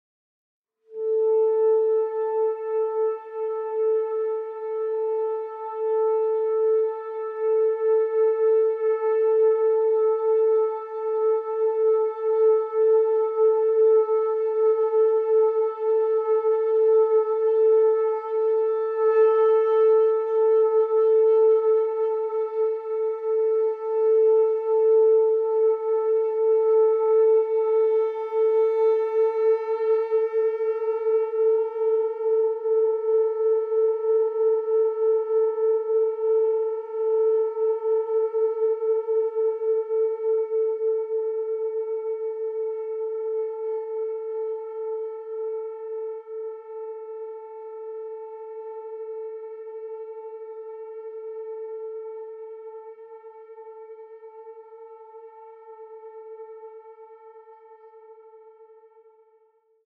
baritone saxophone